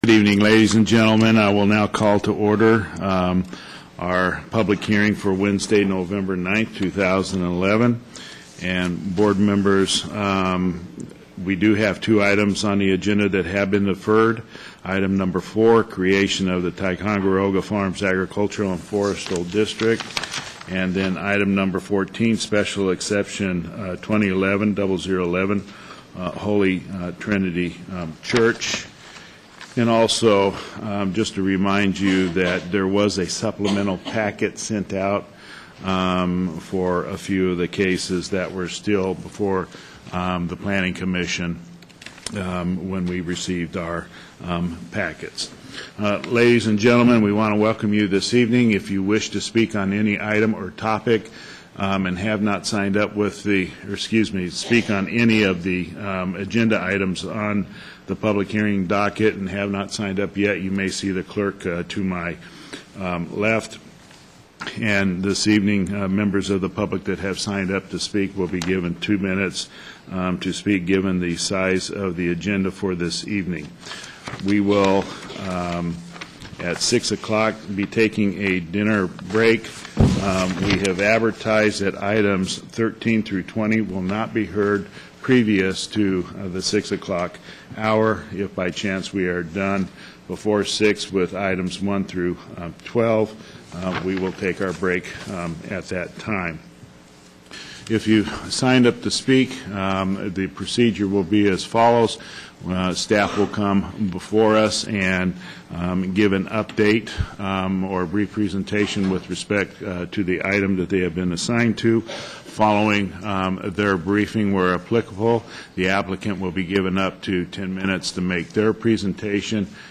Board of Supervisors' Public Hearing 11-09-11 - Nov 09, 2011